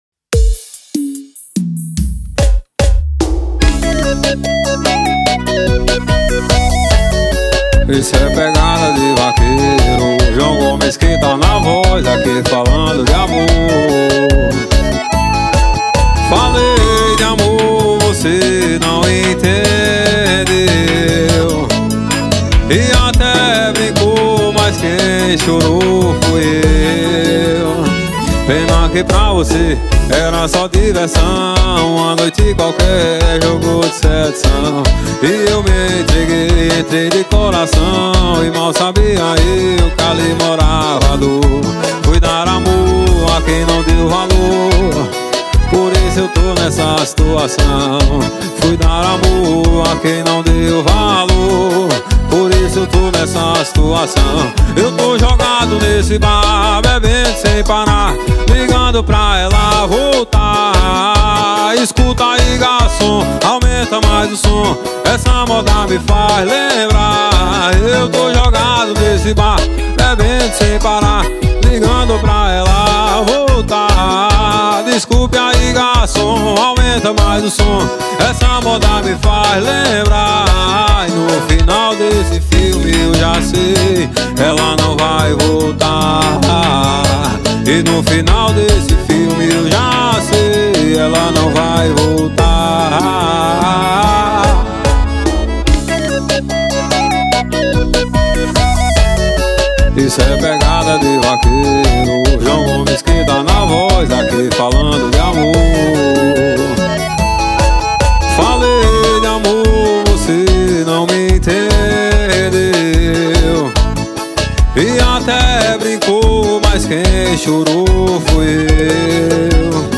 2025-02-01 23:37:41 Gênero: Sertanejo Views